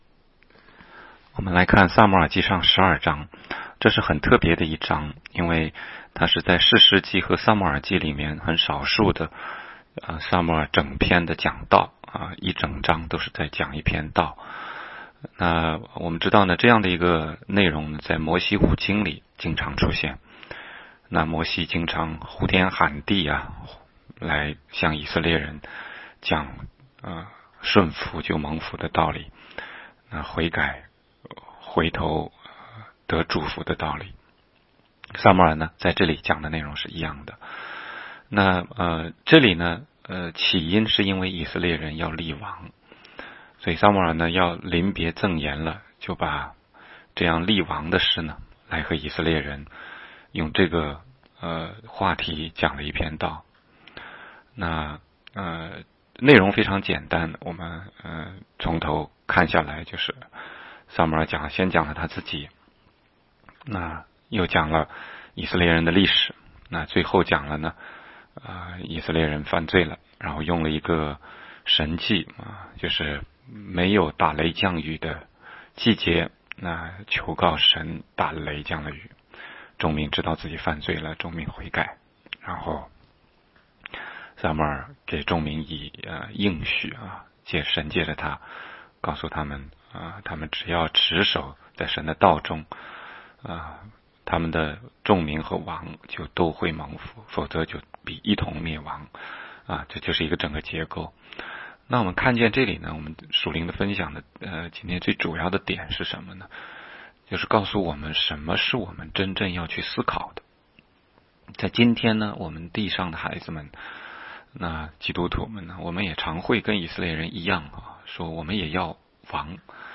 16街讲道录音 - 每日读经-《撒母耳记上》12章
每日读经-撒上12章.mp3